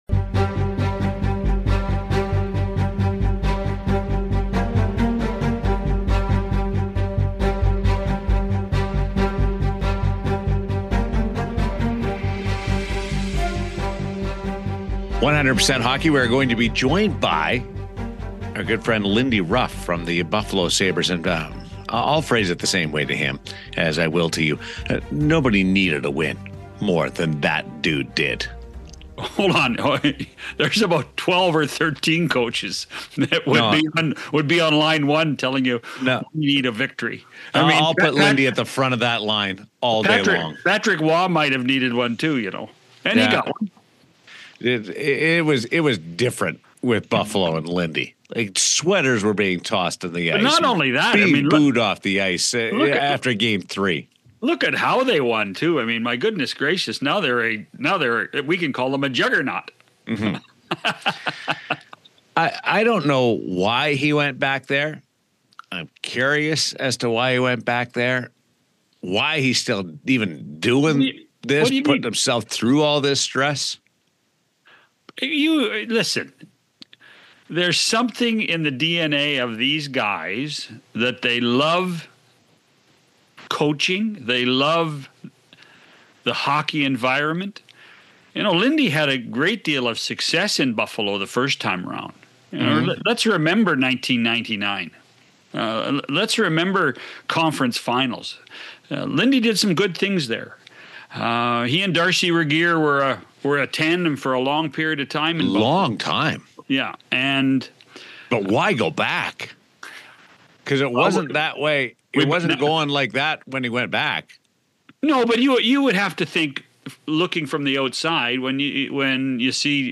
Buffalo Sabres head coach Lindy Ruff joins John Shannon and Daren Millard on 100% Hockey to talk about navigating a tough stretch early in the season.